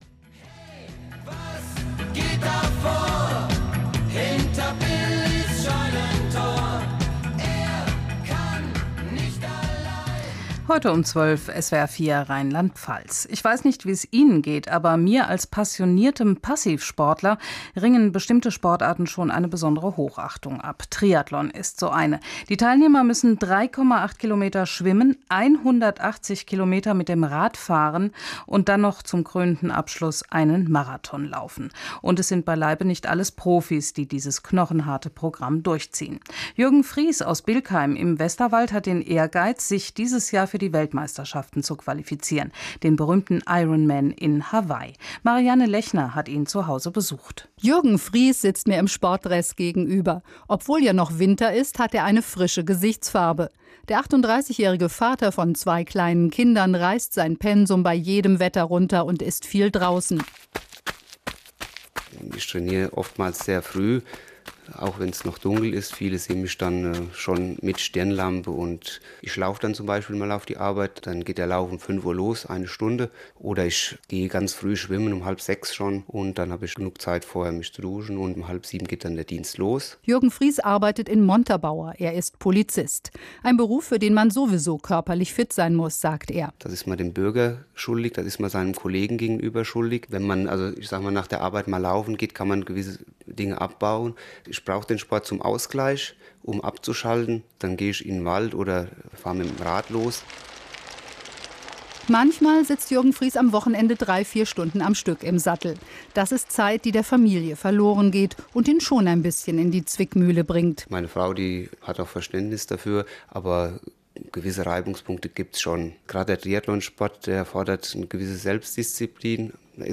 Radiobeitrag in SWR 4 Rheinland-Pfalz „Mittagsmagazin“ im Februar 2007
Interview zum Thema "Qualifikation für den Ironman Hawaii"
interview_swr4.mp3